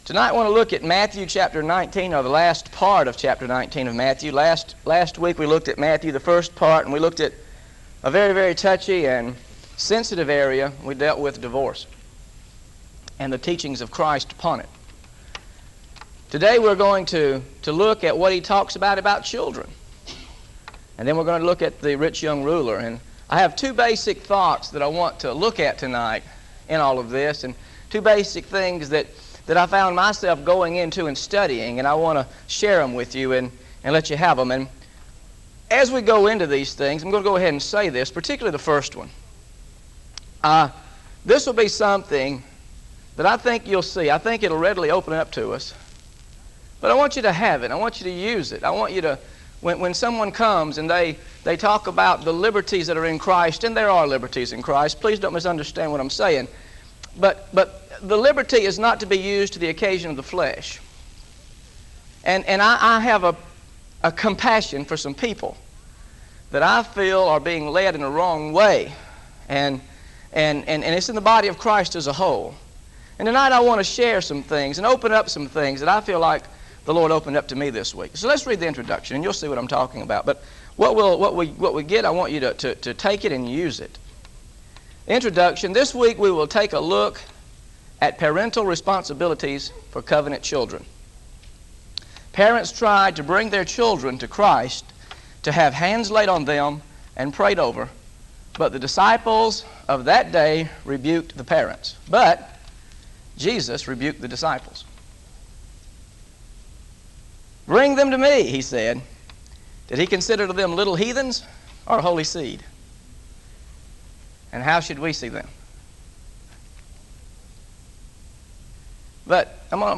GOSPEL OF MATTHEW BIBLE STUDY SERIES This study of Matthew: Matthew 19 verses 13-30 – Rich Young Ruler & Eternal Life is part of a verse-by-verse teaching series through the Gospel of Matthew.